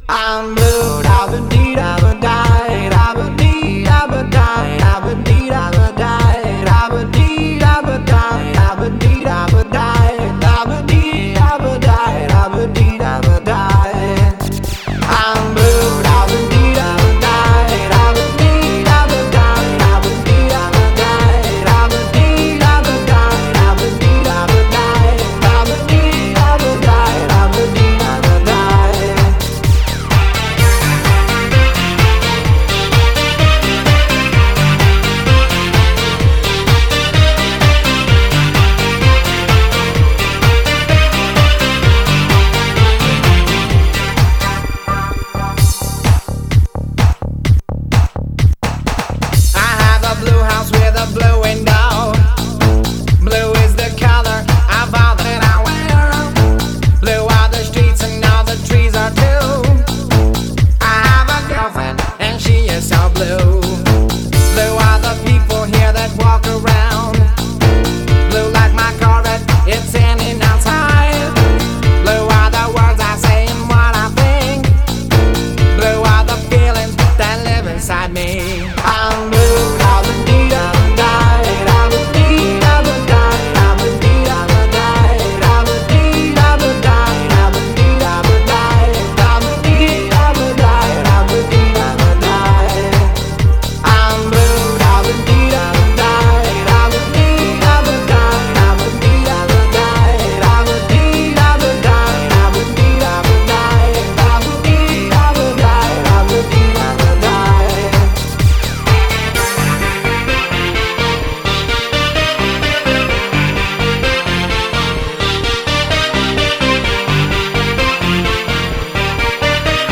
BPM128
Audio QualityPerfect (High Quality)
The music is ripped straight from the original CD.